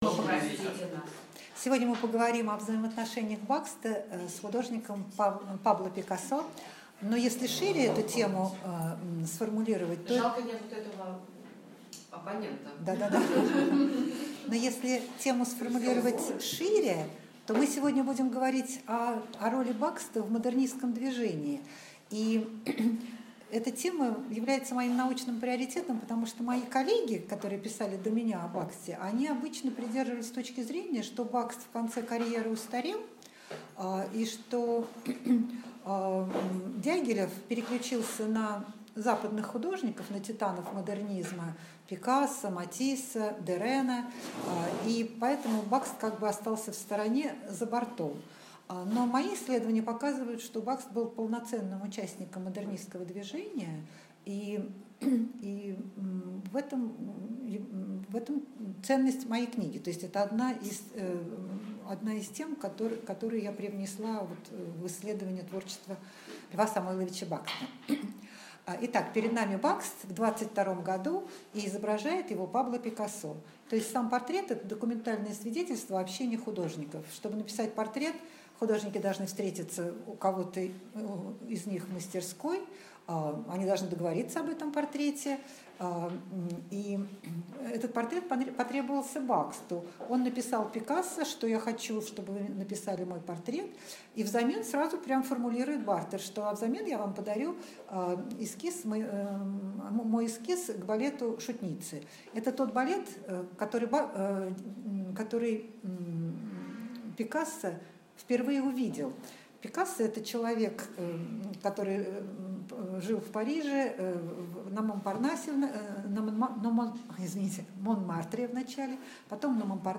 Аудиокнига Бакст и Пикассо | Библиотека аудиокниг